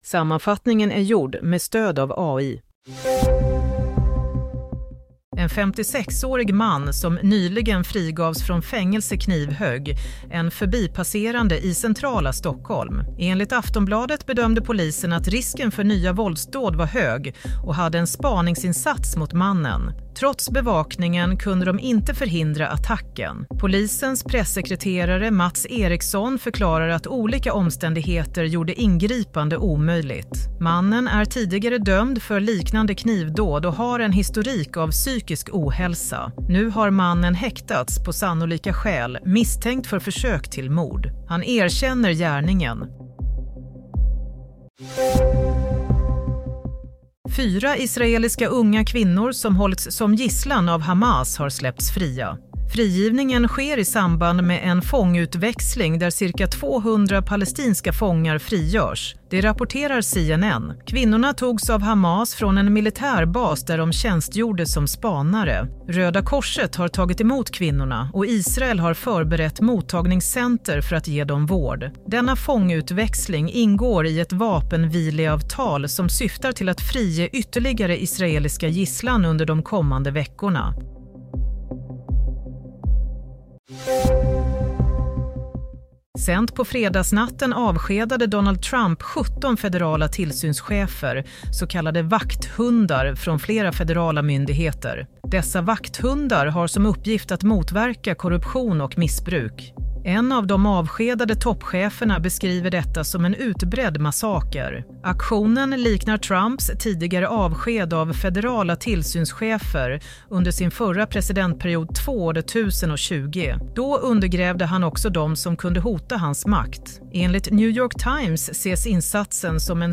Nyhetssammanfattning – 25 januari 16.00
Sammanfattningen av följande nyheter är gjord med stöd av AI.